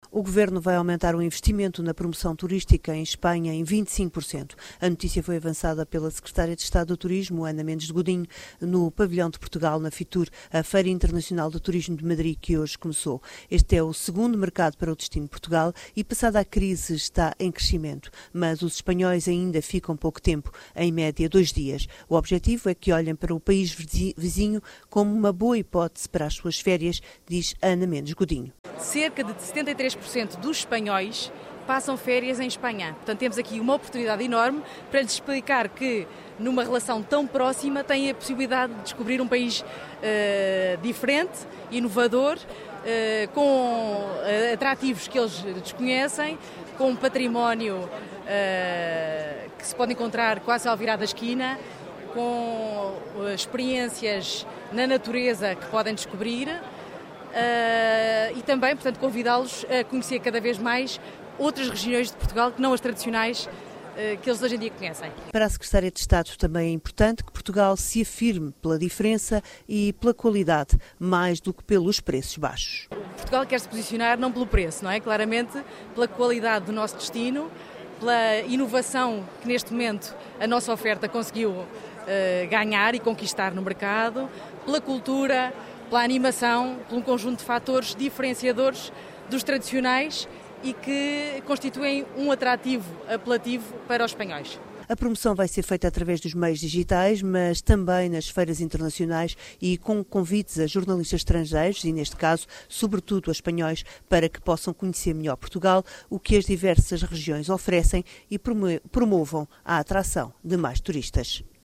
O Governo vai aumentar em 25% o investimento na promoção turística em Espanha, anunciou a secretária de Estado do Turismo, Ana Mendes Godinho, durante a inauguração do Pavilhão de Portugal na Feira Internacional de Turismo de Madrid (FITUR), que começou esta quarta-feira na capital espanhola.
Em declarações à Renascença, Ana Godinho refere que o objectivo é trazer a Portugal cada vez mais espanhóis.